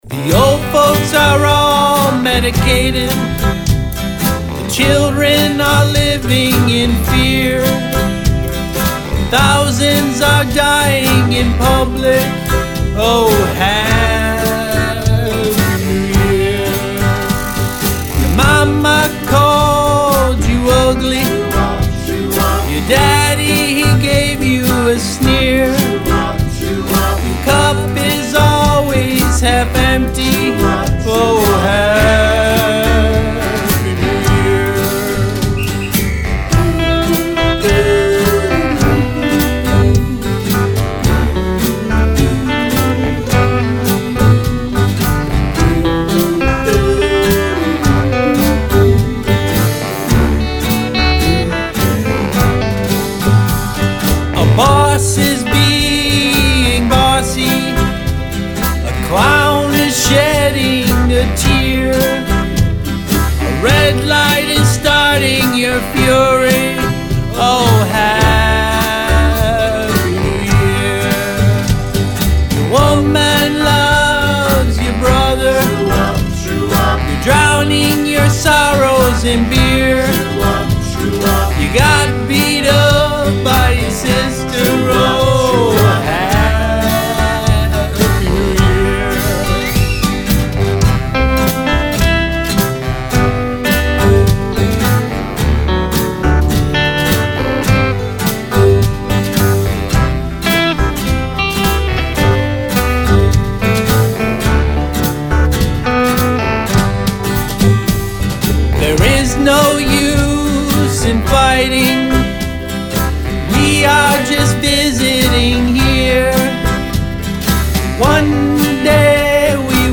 jazz, classical, rock
Trumpet, Rhythm Guitar, Vocals
Tenor Saxophone, Backing Vocals
Lead Guitar
Keyboard